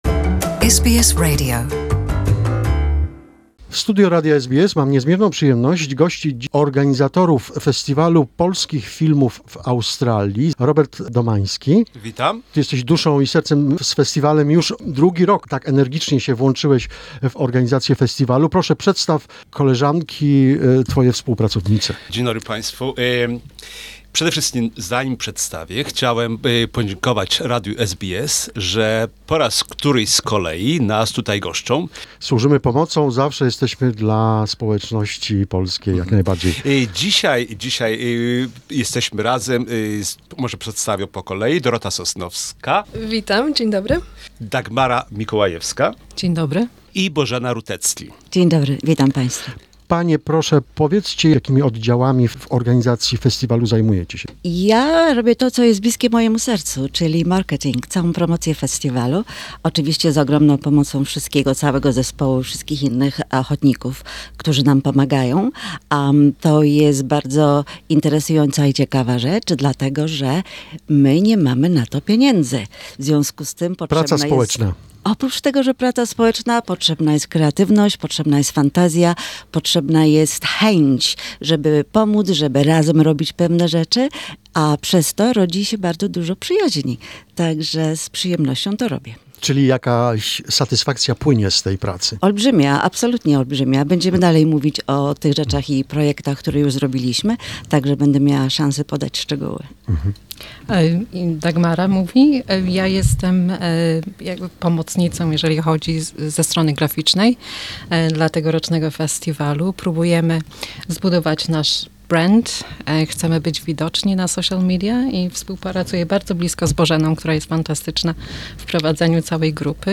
this is the first part of the interview